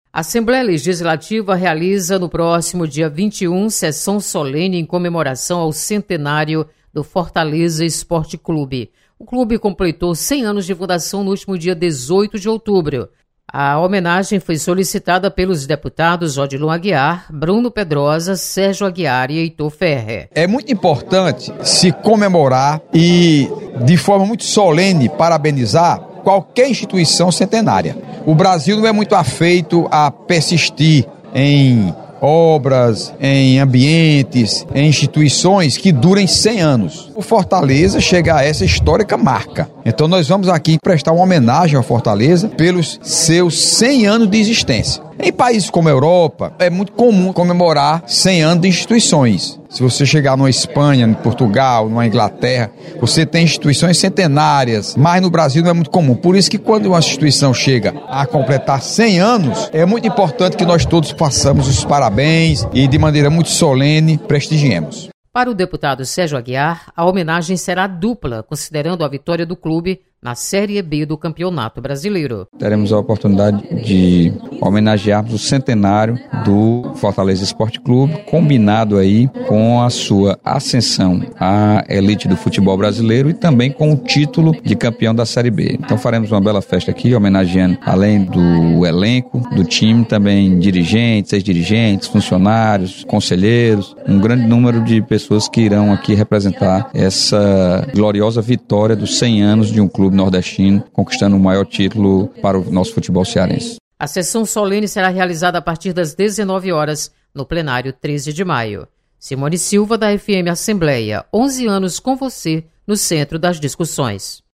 Sessão Solene comemora centenário do Fortaleza Esporte Clube. Repórter